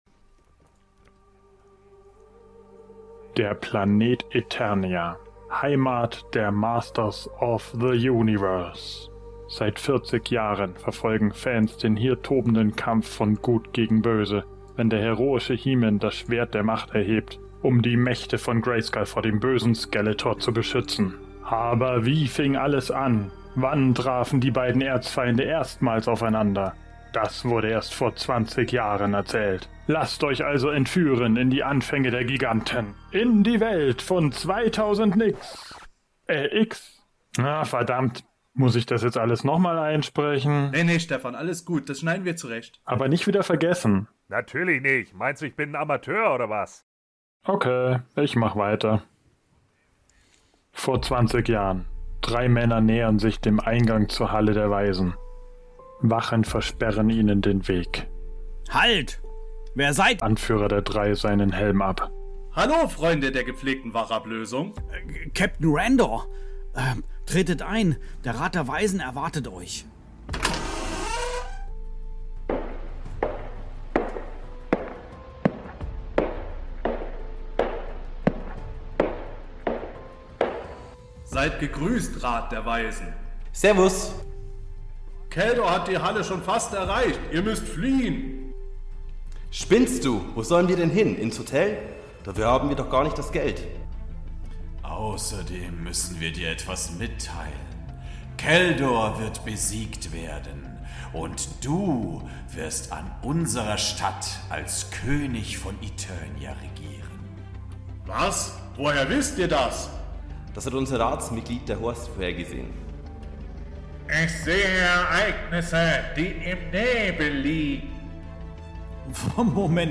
Beschreibung vor 3 Jahren Was für ein Relaunch! 20 Jahre nach Start der "200X" Masters zeigt die Persiflage des HE-MANischen Quartetts, was beim ersten Aufeinandertreffen von He-Man und Skeletor wirklich geschah. Pubertierende Prinzen, schizophrene Zauberer und eine Mauer die weg muss, erwarten euch in unserem Comedy-Hörspiel!